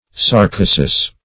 Search Result for " sarcosis" : The Collaborative International Dictionary of English v.0.48: Sarcosis \Sar*co"sis\, n. [NL., fr. Gr.
sarcosis.mp3